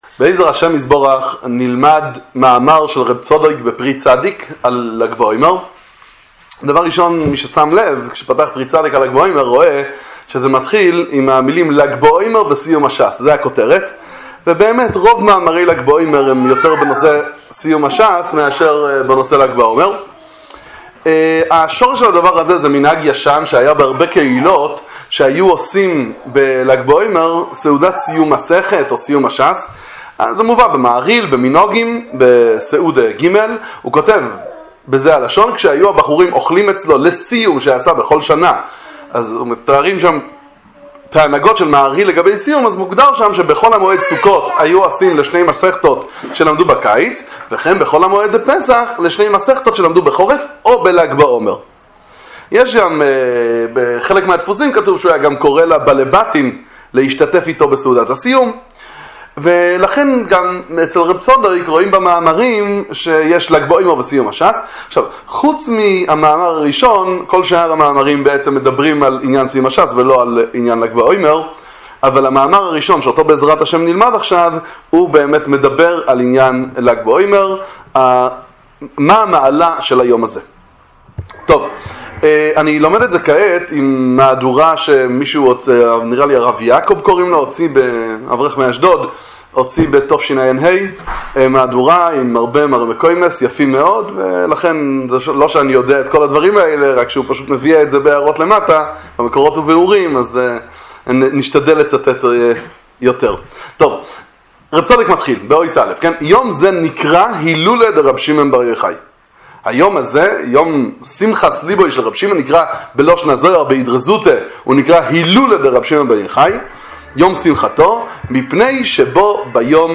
שיעור תורה לצפיה בספר פרי צדיק על ל"ג בעומר